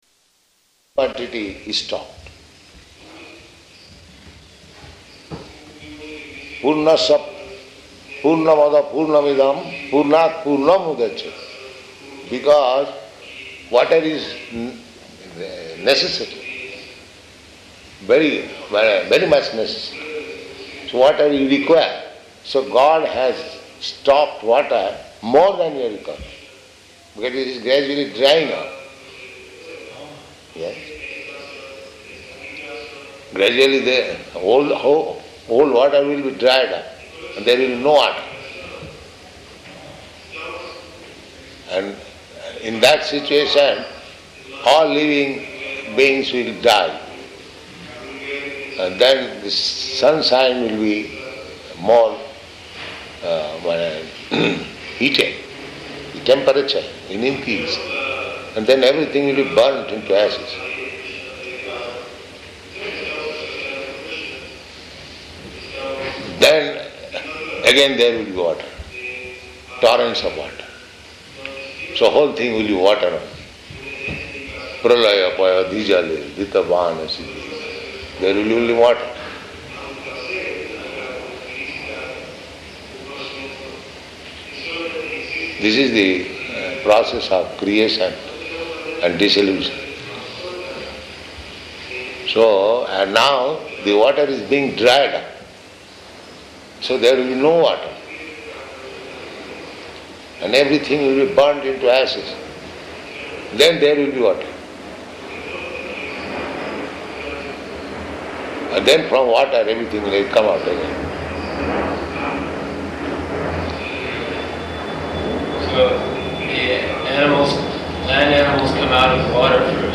Room Conversation
Room Conversation --:-- --:-- Type: Conversation Dated: June 4th 1972 Location: Mexico City Audio file: 720604R3.MEX.mp3 Prabhupāda: ...quantity He stocked. pūrṇasya, pūrṇam adaḥ pūrṇam idaṁ pūrṇāt pūrṇam udacyate.